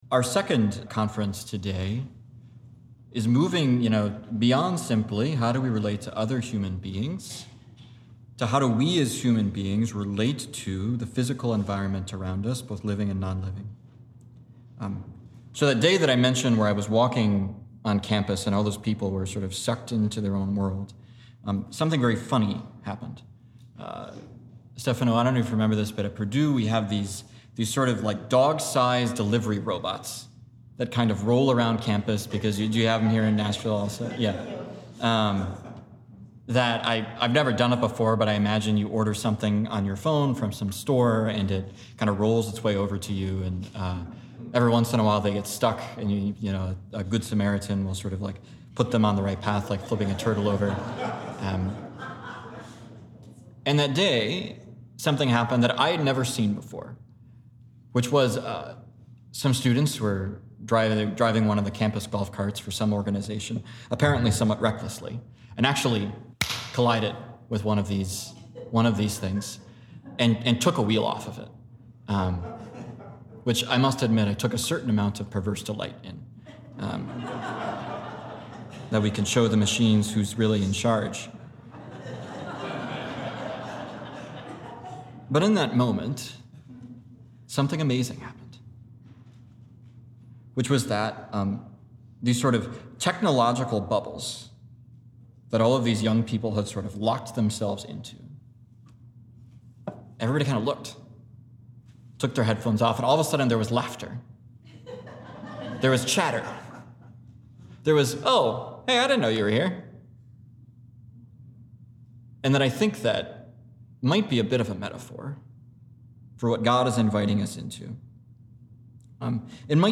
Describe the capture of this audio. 2025-2026 Chapter Year 2025 Day of Recollection - Conference 2 Play Episode Pause Episode Mute/Unmute Episode Rewind 10 Seconds 1x Fast Forward 30 seconds 00:00 / 00:32:52 Subscribe Share RSS Feed Share Link Embed